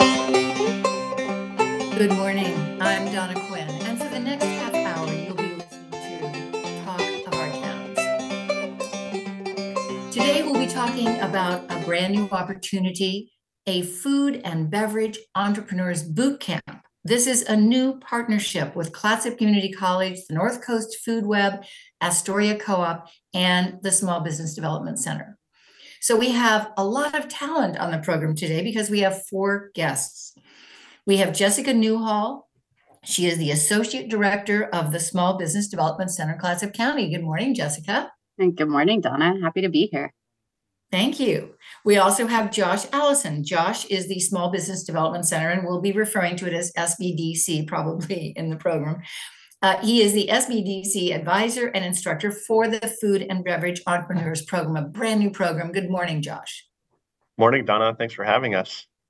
Editor Note: Technical errors resulted in low quality intro and outro for this broadcast recording.